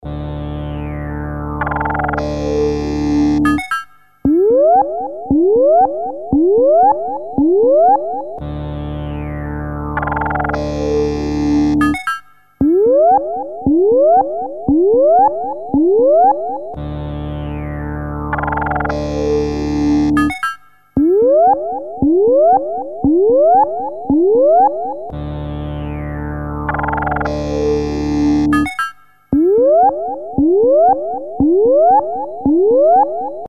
So here’s my ringtone.
Eight seconds of
looped 4 times